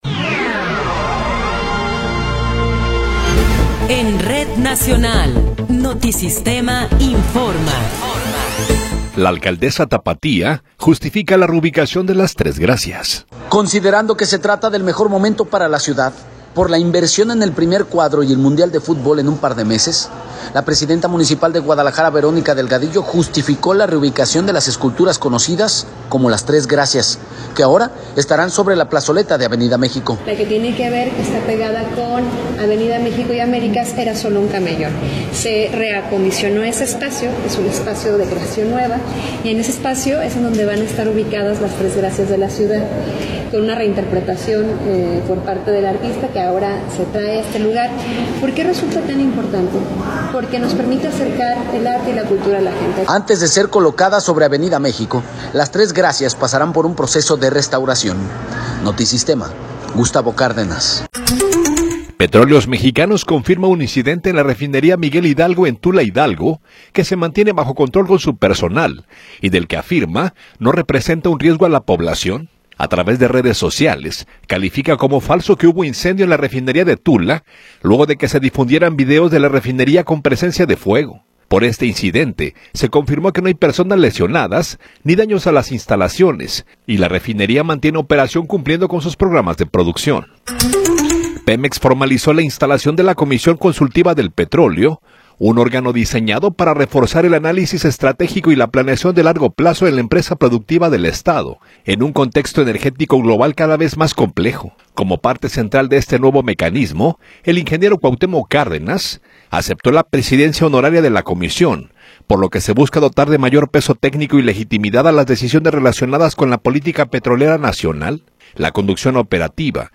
Noticiero 17 hrs. – 16 de Abril de 2026